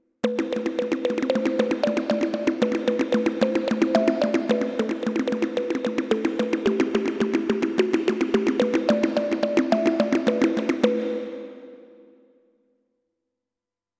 21. I SUONI - GLI STRUMENTI XG - GRUPPO "SYNTH EFFECTS"
09. Synt Dr. Comp.
XG-12-09-SynthDrComp.mp3